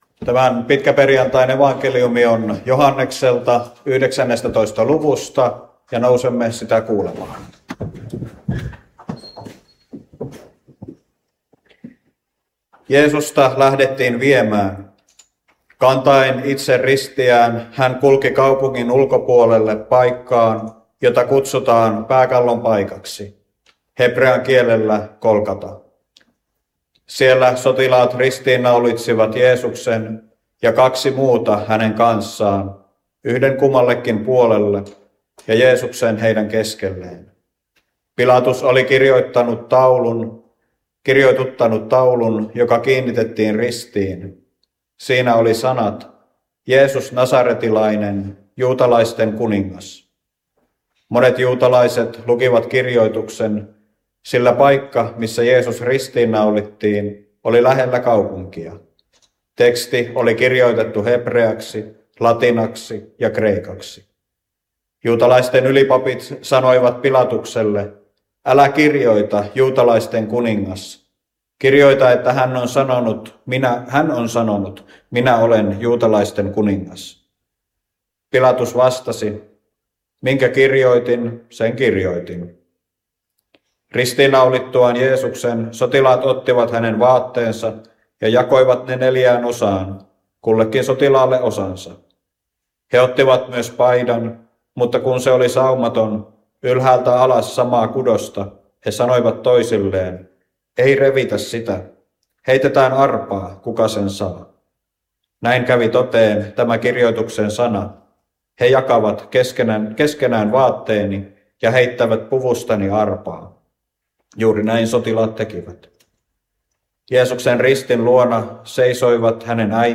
saarna Soinissa pitkäperjantaina Tekstinä Joh. 19:16–30